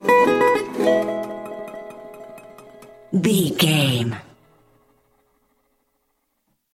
Ionian/Major
acoustic guitar
electric guitar
ukulele
slack key guitar